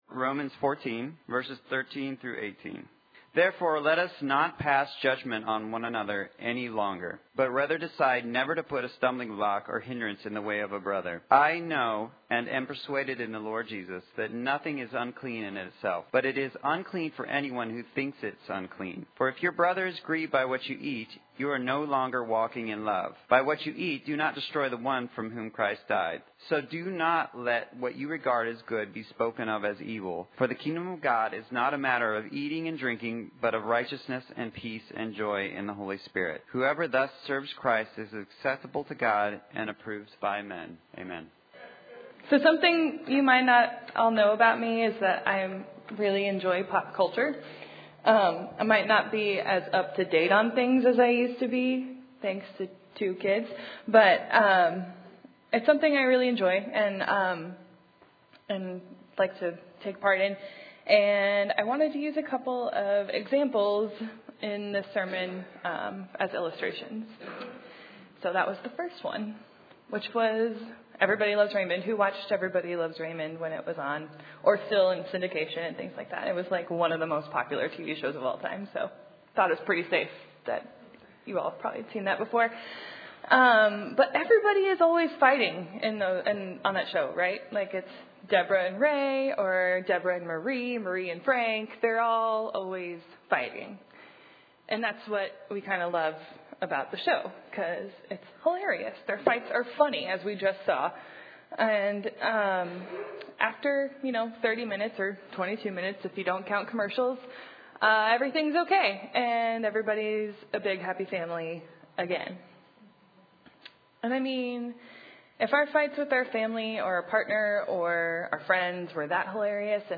The two clips mentioned in the sermon were from the TV shows “Everyone Loves Raymond”, and “Gilmore Girls”.